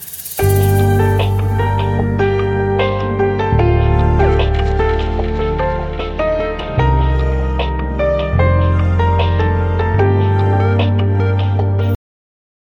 آهنگ زنگ بی کلام